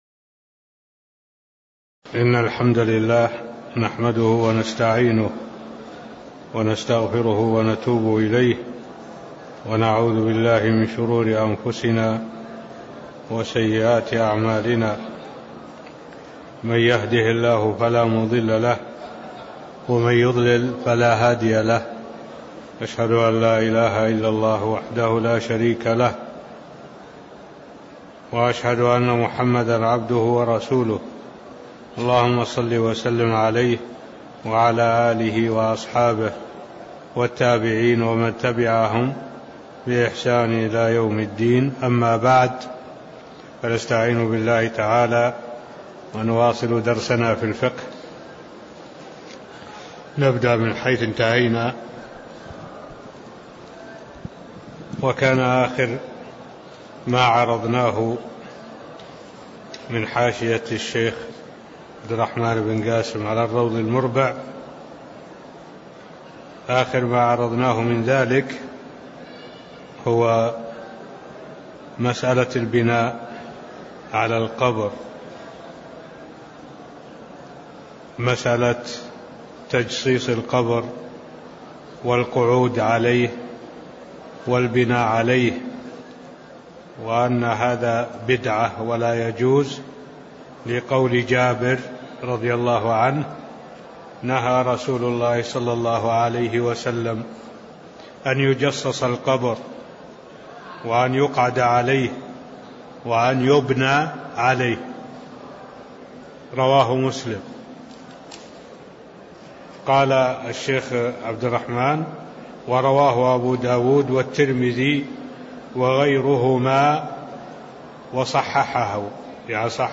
تاريخ النشر ١٨ صفر ١٤٢٩ هـ المكان: المسجد النبوي الشيخ: معالي الشيخ الدكتور صالح بن عبد الله العبود معالي الشيخ الدكتور صالح بن عبد الله العبود تسوية القبر (009)